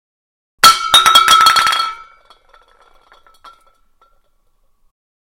Звуки освежителя воздуха
Громкий звук падения баллона освежителя воздуха на пол в туалете